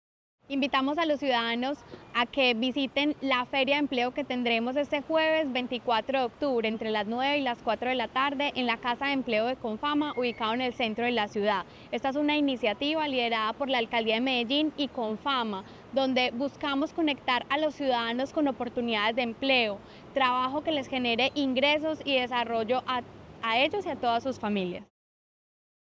Palabras de María Fernanda Galeano, secretaria de Desarrollo Económico